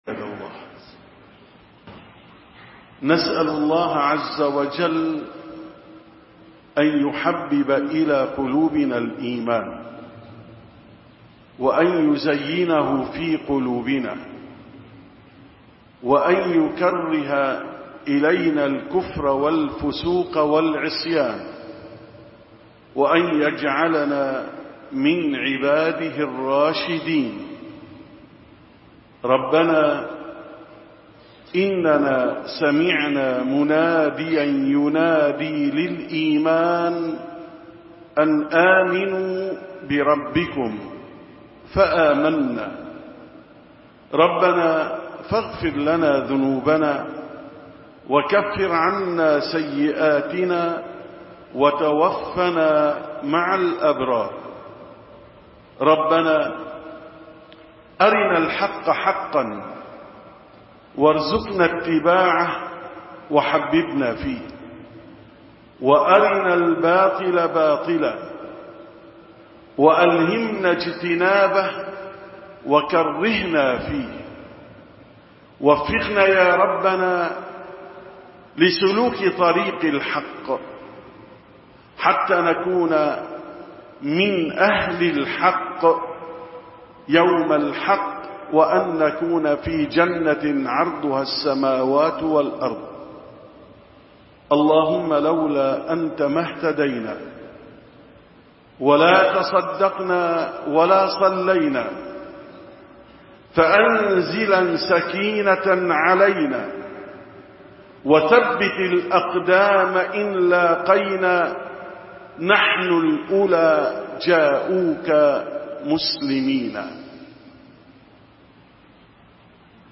881ـ خطبة الجمعة: لا حياة بدون إيمان